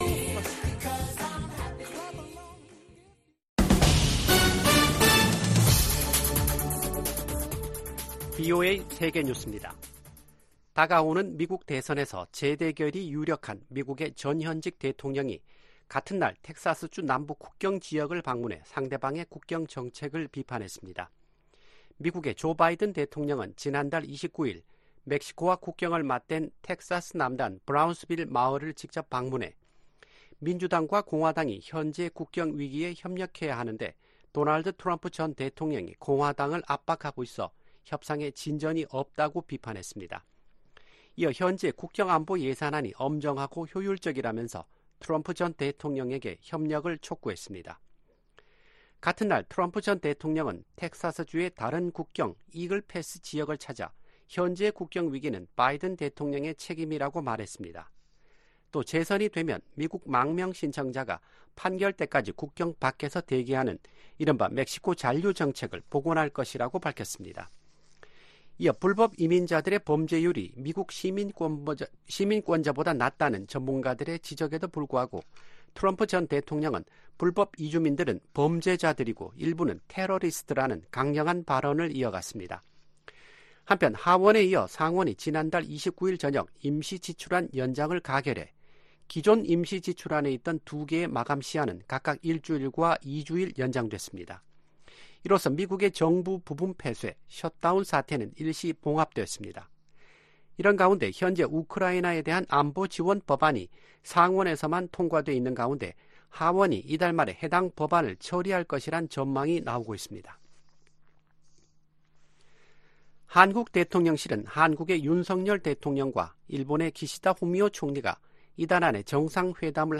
VOA 한국어 아침 뉴스 프로그램 '워싱턴 뉴스 광장' 2024년 3월 2일 방송입니다. 북한이 미국의 우주 체계를 위협할 사이버전과 전자전 역량을 갖췄다고 미군 고위 당국자들이 평가했습니다. 윤석열 한국 대통령은 제105주년 3.1절 기념식에서 북한에 자유와 인권을 확장하는 게 통일이라고 말했습니다. 커트 캠벨 미 국무부 부장관이 방미 중인 조태열 한국 외교부 장관을 만나 북한 문제와 북-러시아 무기거래, 미한동맹 강화 등을 논의했습니다.